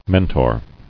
[men·tor]